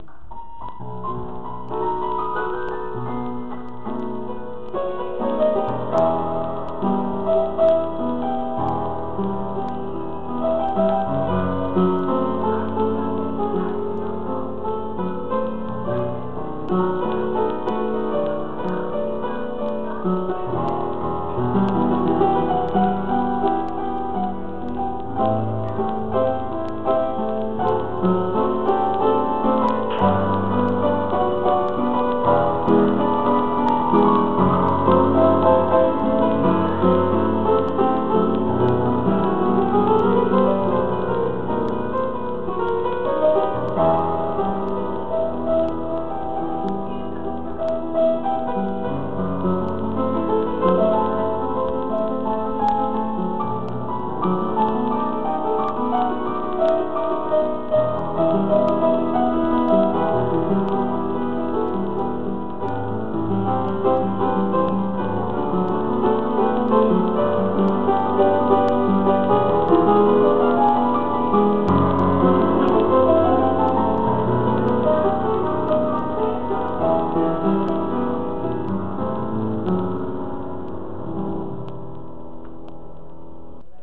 [Lezione di classico del 28 maggio 2011, Adagio al centro in 3/4, improvvisazione]
inner-echo-adagio.mp3